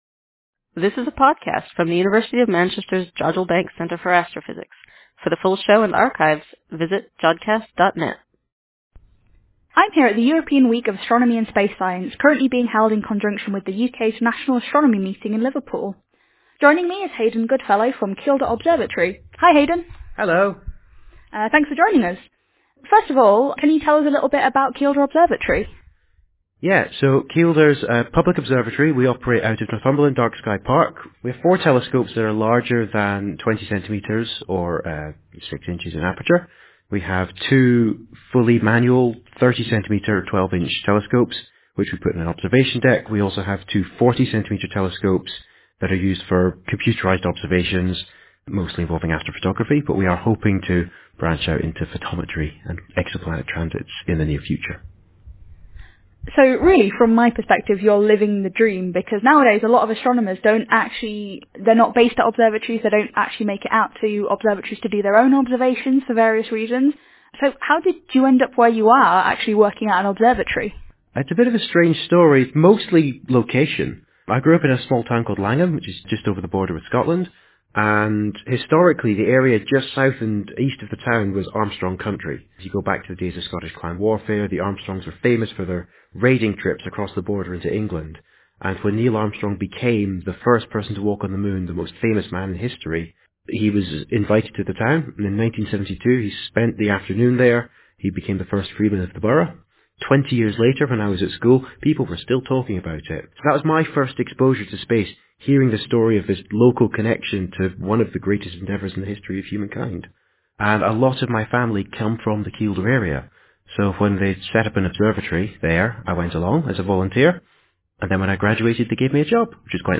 In this special episode we have an EWASS/NAM extravaganza, brought to you from the floor of the cross-over event held in Liverpool in April 2018, where the European Week of Astronomy and Space Science (EWASS) met the UK's National Astronomy Meeting (NAM).
Interview